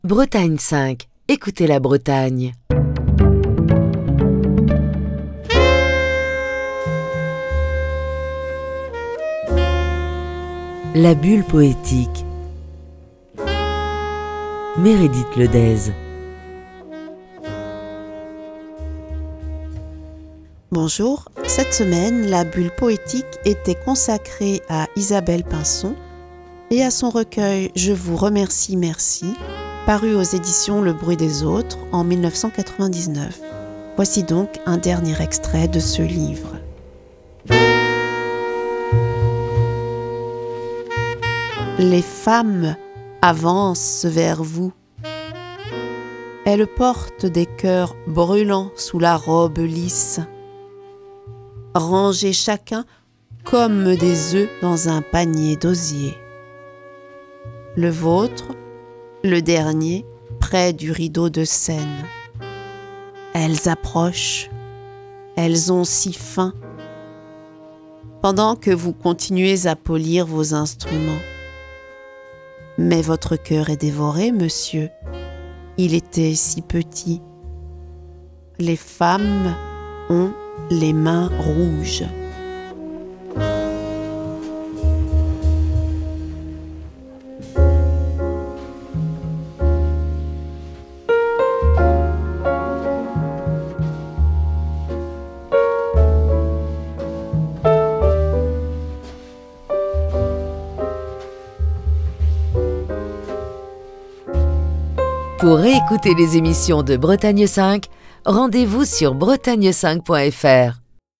a lu quelques poèmes